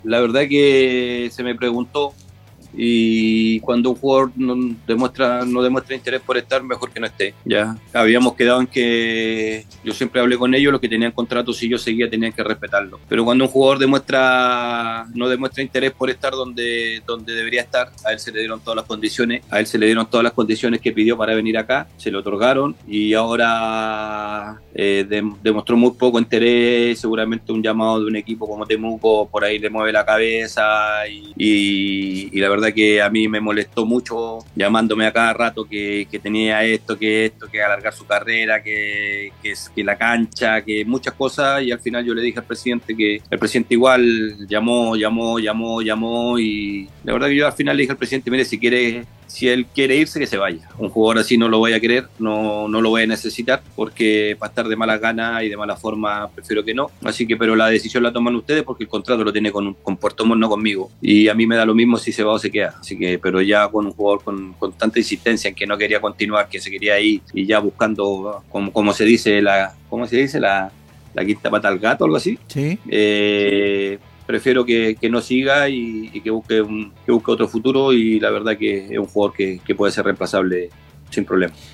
En diálogo con Puntero Izquierdo